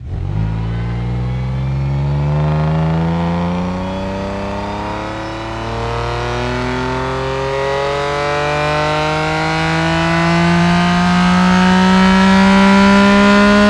rr3-assets/files/.depot/audio/Vehicles/v6_01/v6_01_accel.wav
v6_01_accel.wav